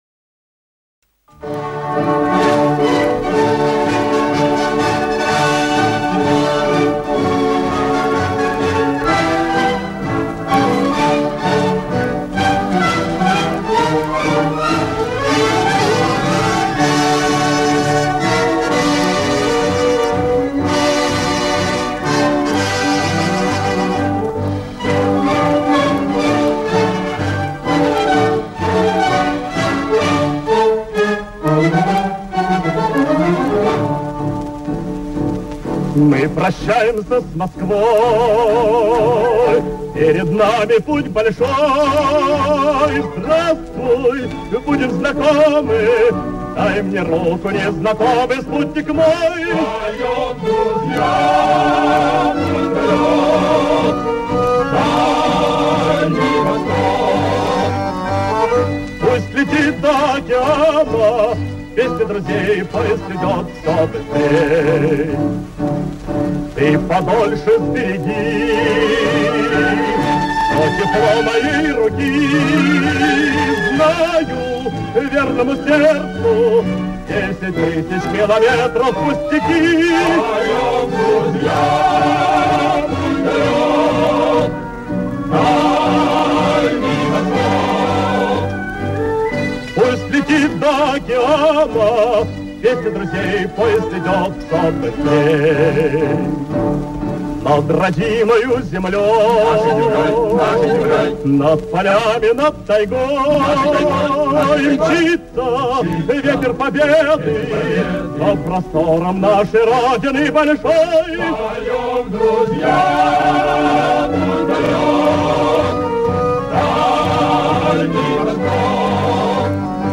Вариант с хором и тремя куплетами